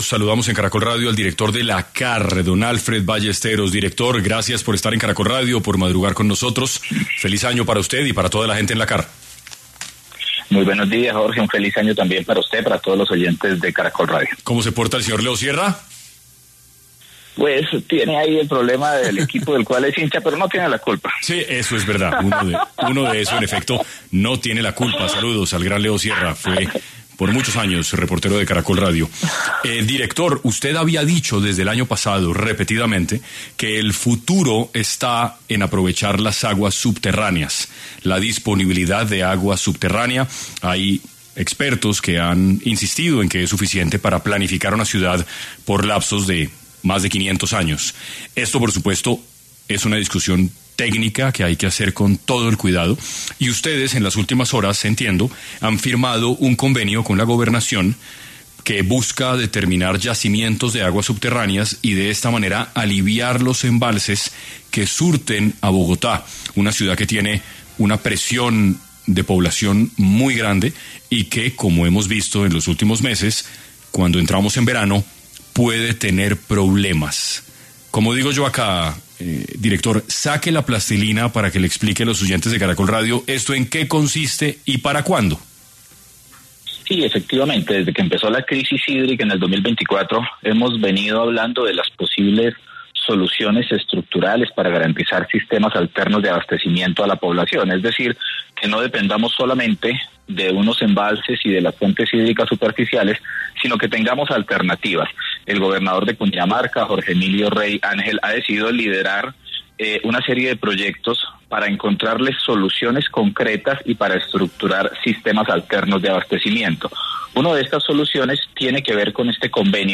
En 6AM de Caracol Radio estuvo Alfred Ballesteros, director de la CAR Cundinamarca, quien habló sobre el convenio que firmó con la Gobernación para que algunos municipios de la Sabana dejen de depender del Acueducto de Bogotá